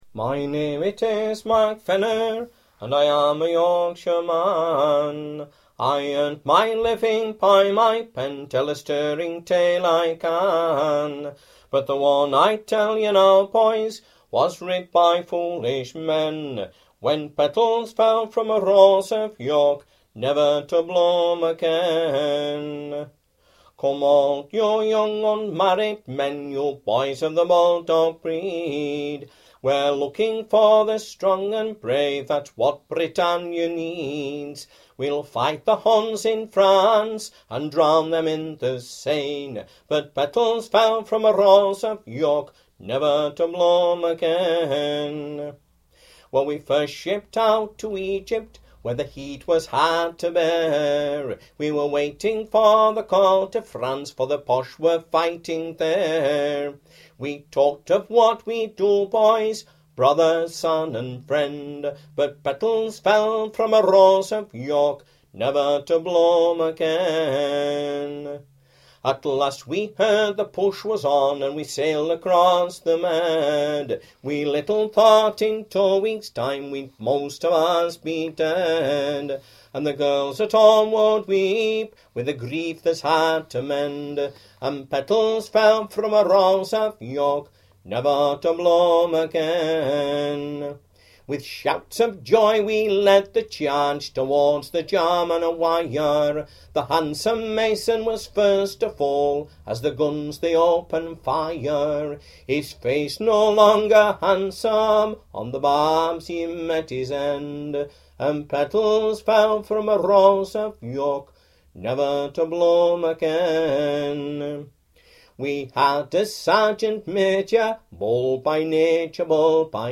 Military
Dm